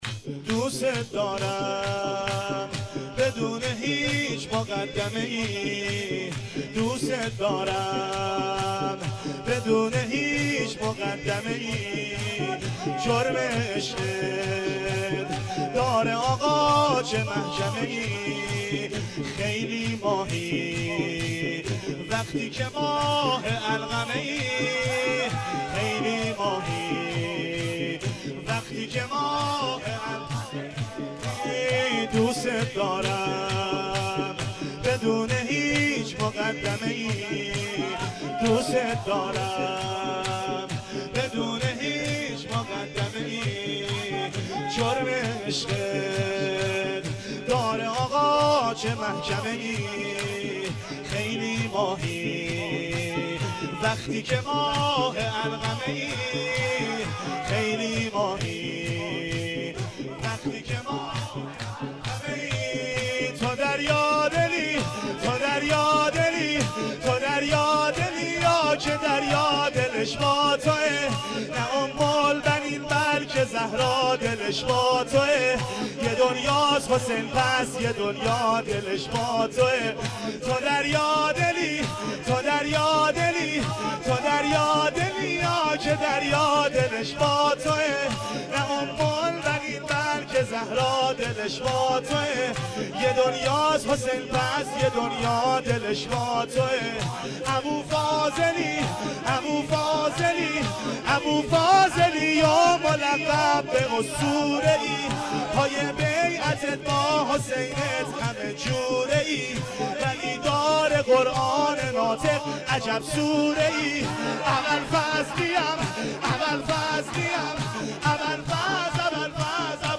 بی دلبر شده علی (شور): متاسفانه مرورگر شما، قابیلت پخش فایل های صوتی تصویری را در قالب HTML5 دارا نمی باشد.